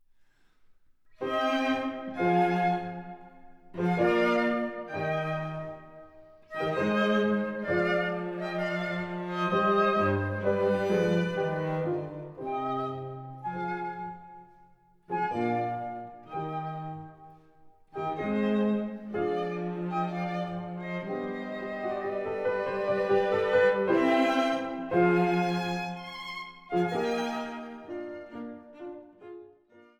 Flöte
Violoncello
Klavier
Das Cembalo wird durch das Klavier ersetzt.